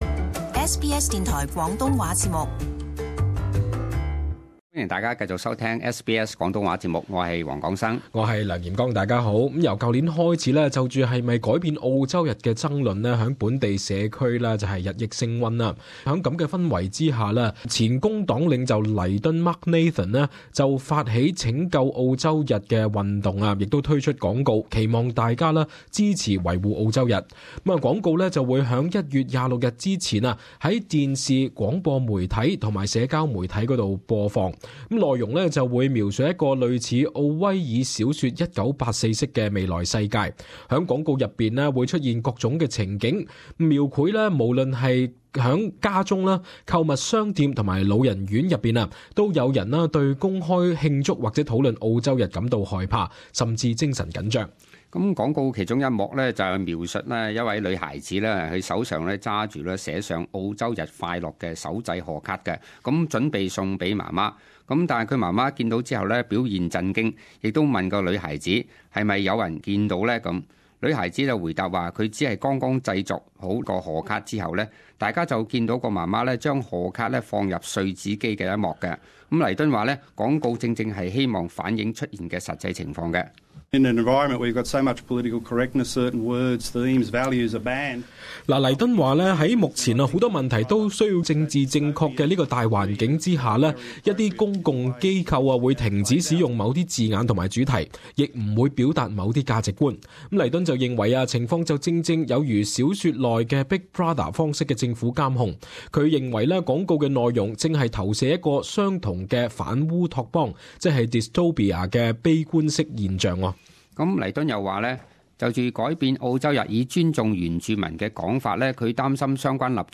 【時事報導】前工黨領袖推「拯救澳洲日」廣告惹爭論